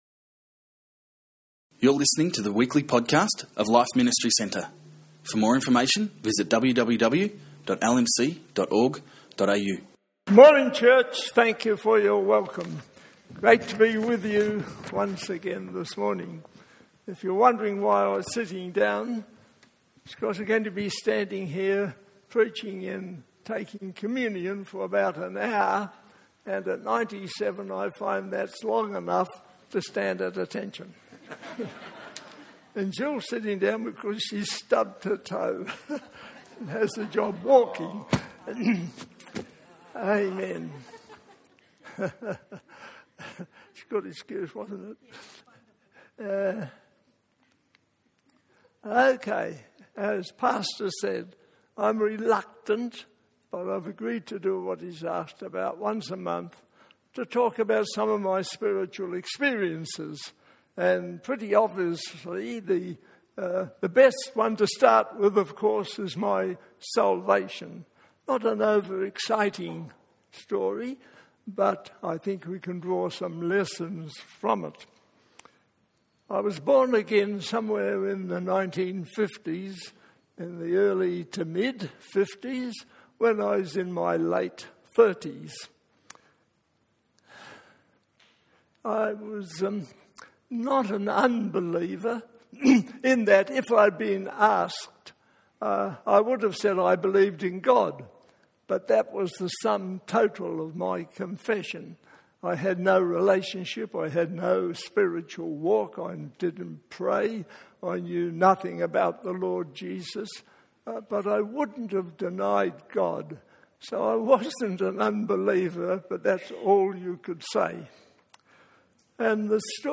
message